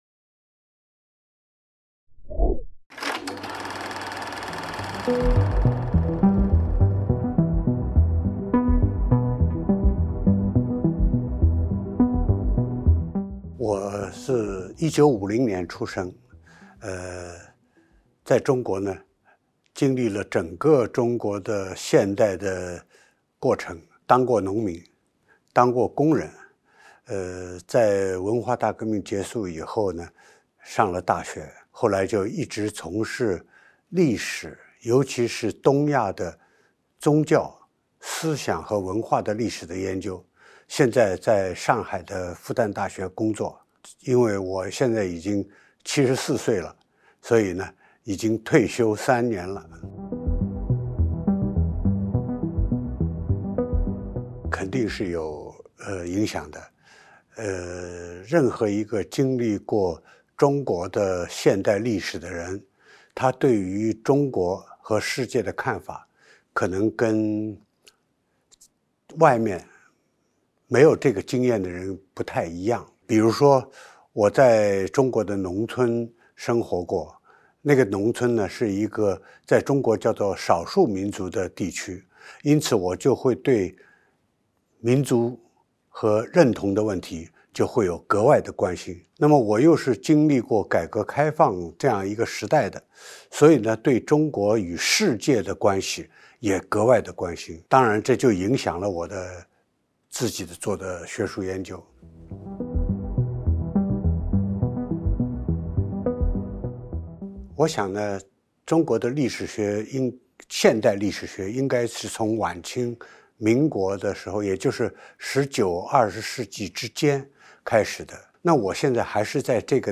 Interview de Zhaoguang Ge, dans le cadre de la sortie de son ouvrage : Qu'est-ce que la Chine ? Territoires, ethnies, cultures et histoire